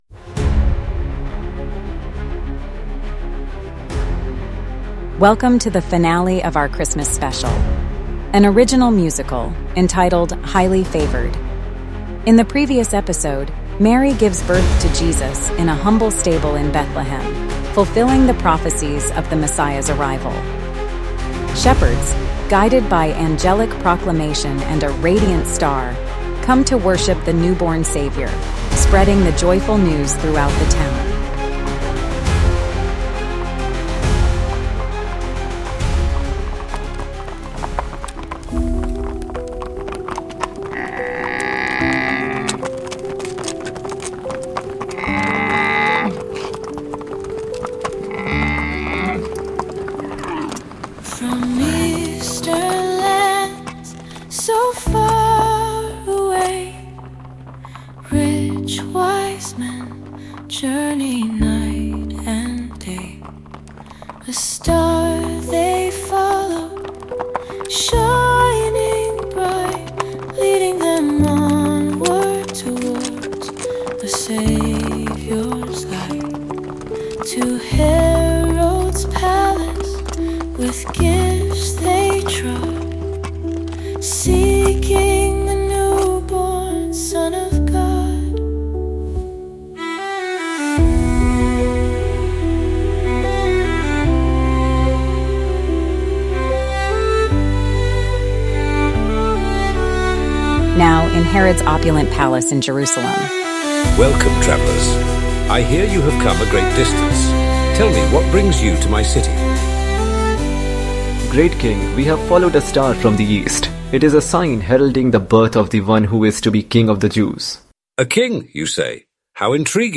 Highly Favored - The Musical (Finale)